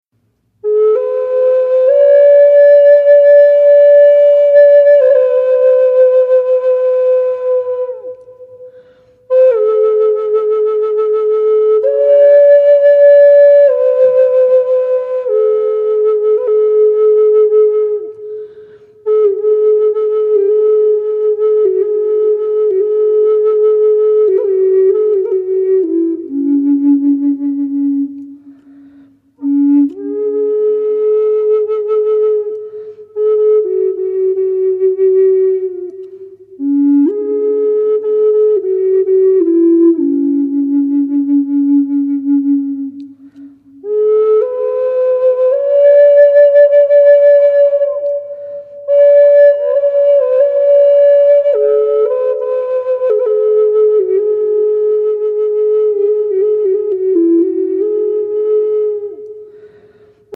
Native American Flute & Folk Music